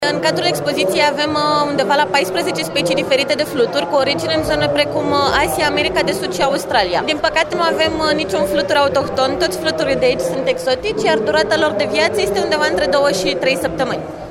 Distracţie de 1 Iunie la mall în Brașov